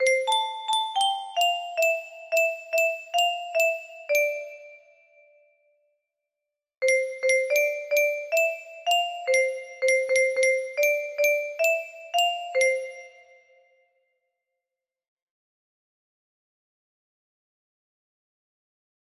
kingston music box melody